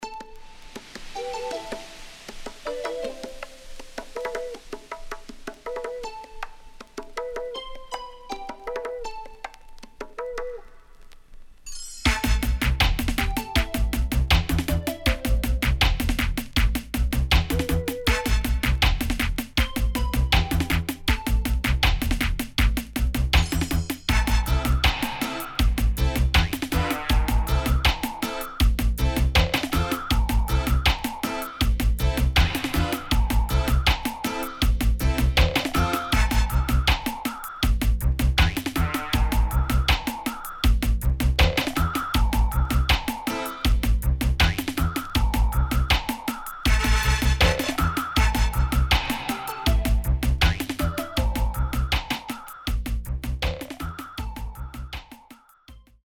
少しチリノイズ入りますが良好です。